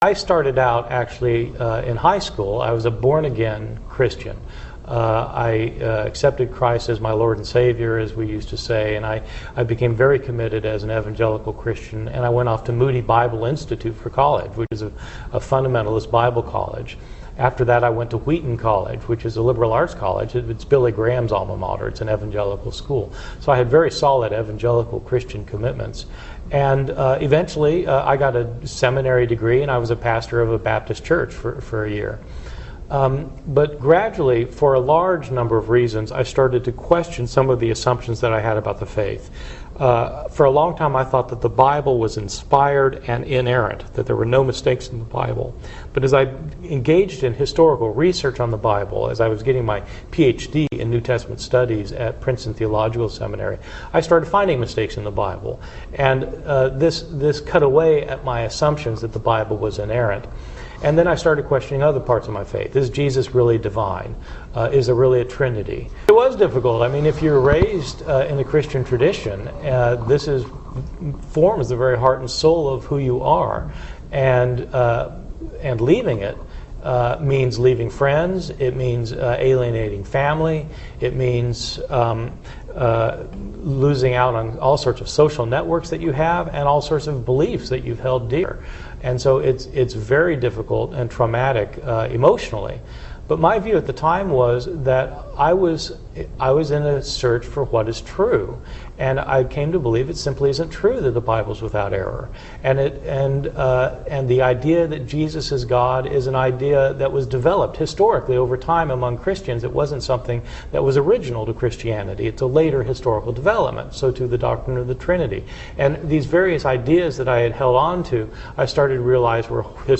This is a short interview with a renowned Bible scholar who talks about why he left Christianity.